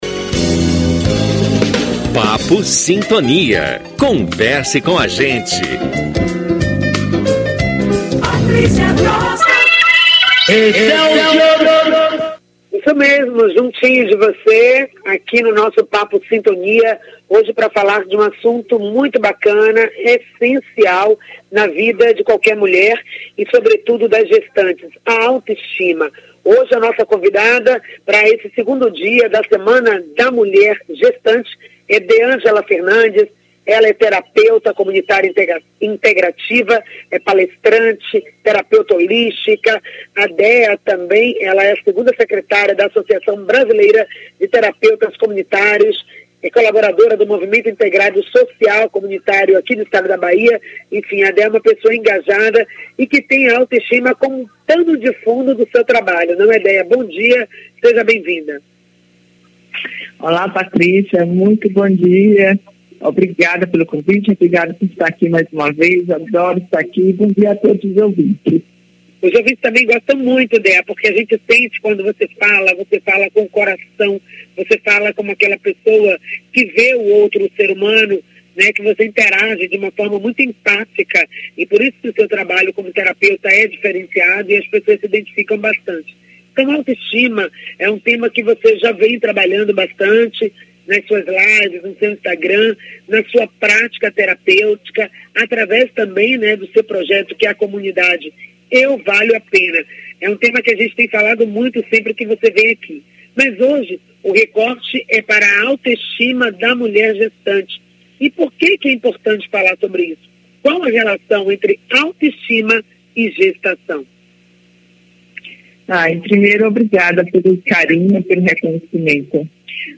Tema da entrevista a Auto- estima da gestante.